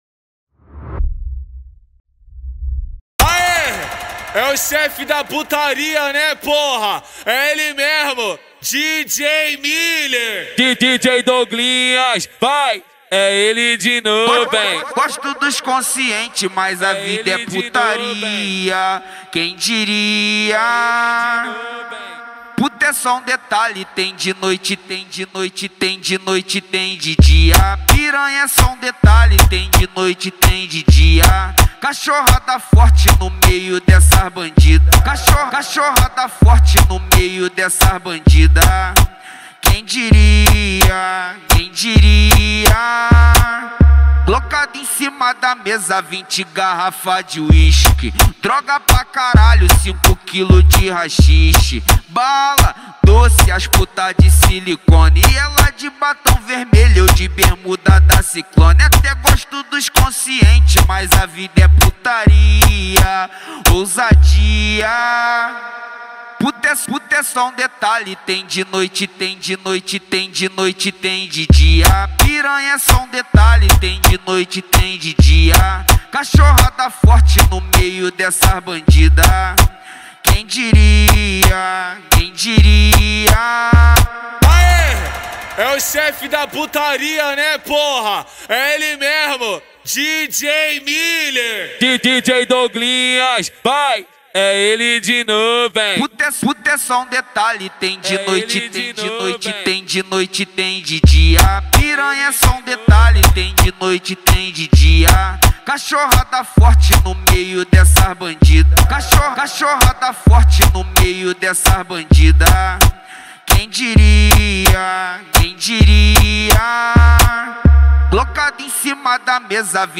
2025-01-27 17:38:39 Gênero: MPB Views